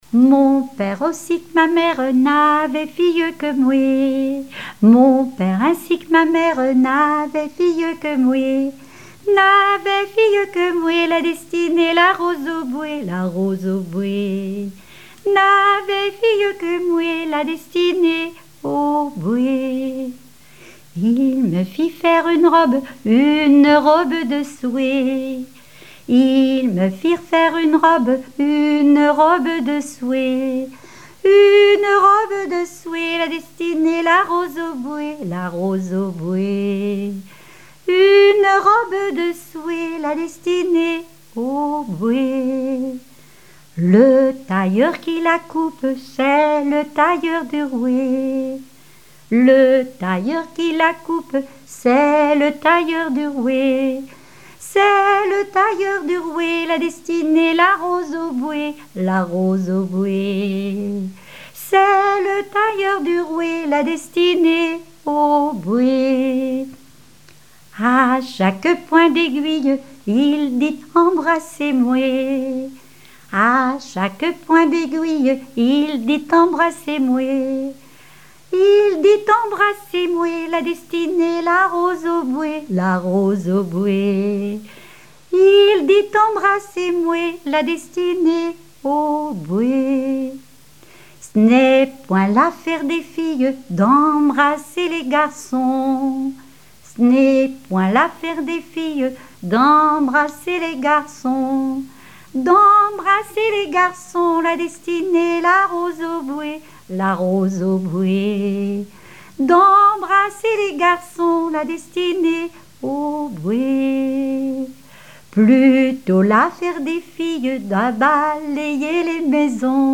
Genre laisse
chansons populaires et traditionnelles
Pièce musicale inédite